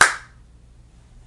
玩具敲击乐 " 玩具手榴弹
描述：用3个不同的电容话筒录制的打击乐套件和循环，用Wavosaur编辑。
标签： 敲击 震动 塔姆伯林 玩具
声道立体声